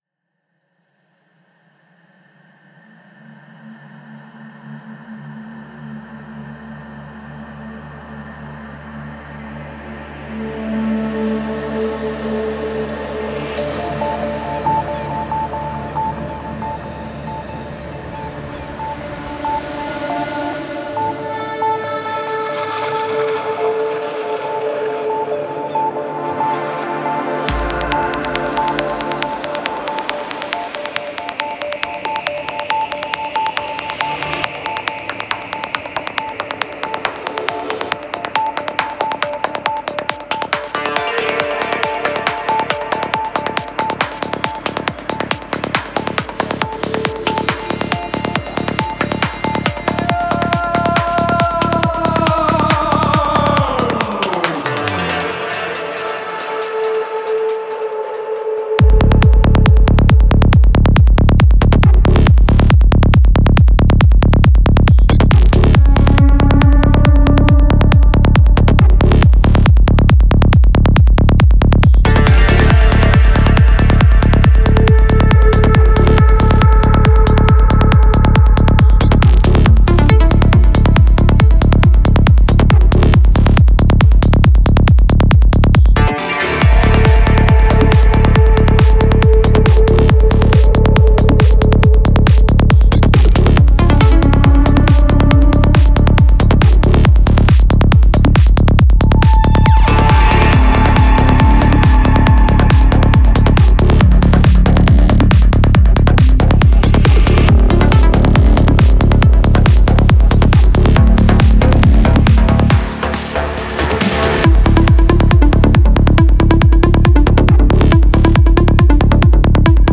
Файл в обменнике2 Myзыкa->Psy-trance, Full-on
Стиль: Trance / Psy Trance